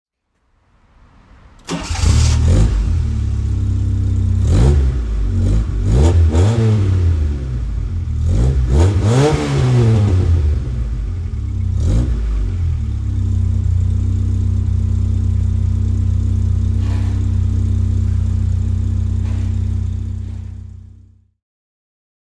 Fiat 127 Sport (1982) - Starten und Leerlauf